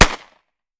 9mm Micro Pistol - Gunshot B 001.wav